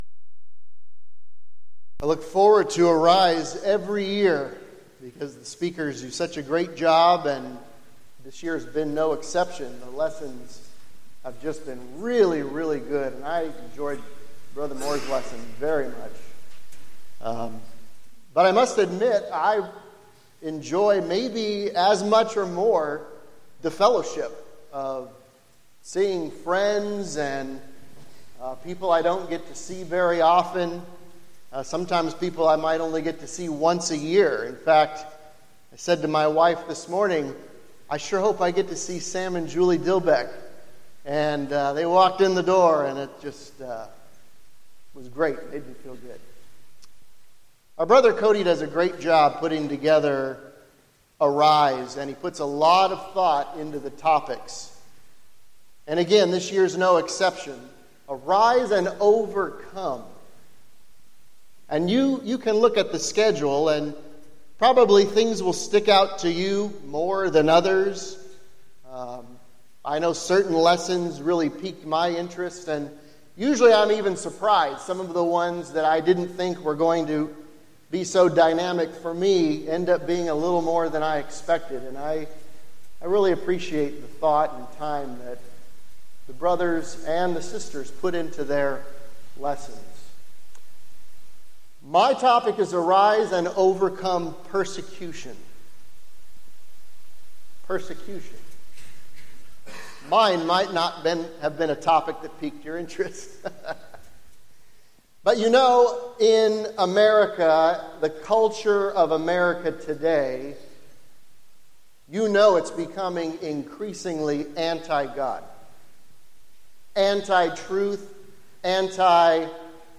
Event: 6th Annual Southwest Spiritual Growth Workshop
lecture